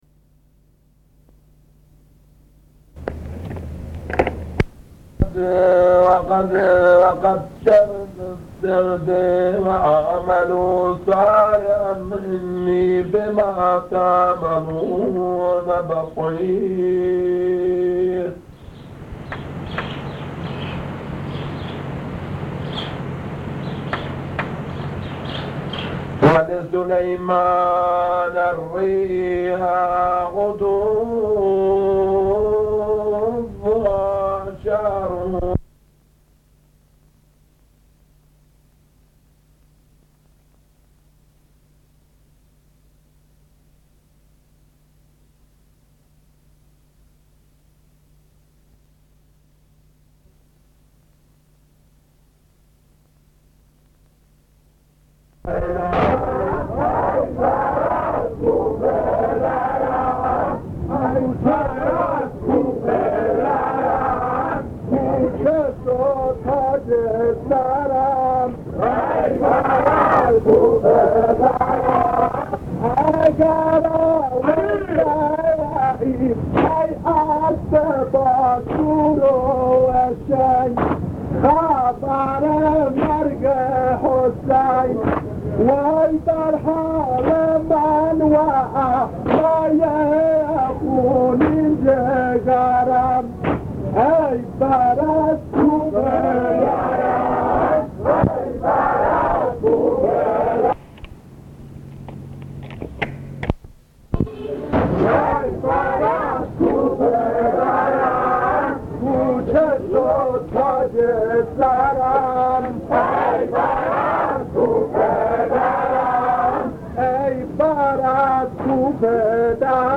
صدای ماندگار/ نوحه سرایی